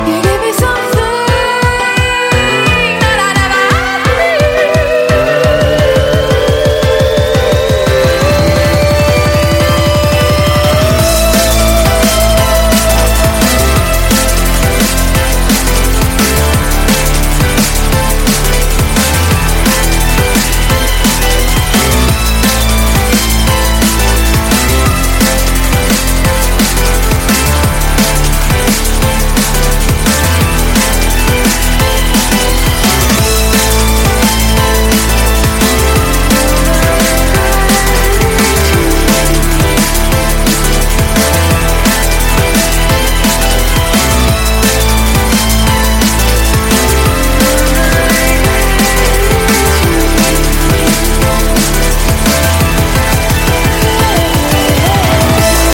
Drum'n'bass dubstep